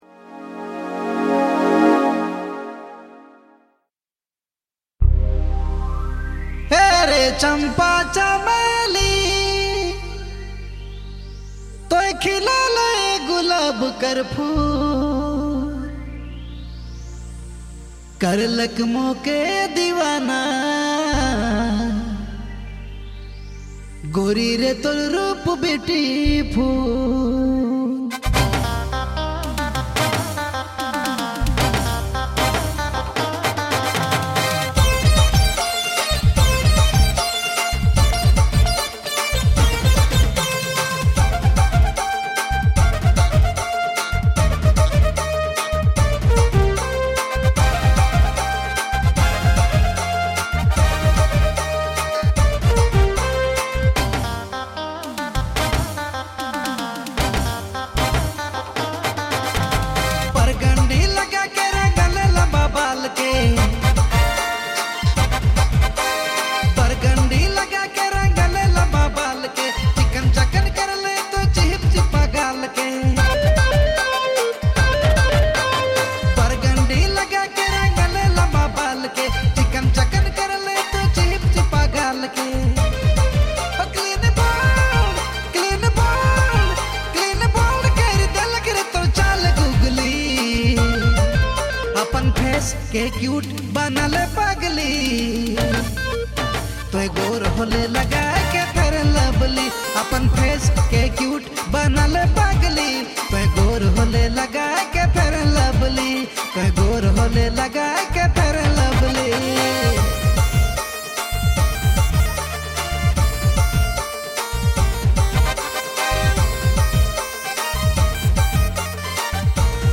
Dj Remixer
February Months Latest Nagpuri Songs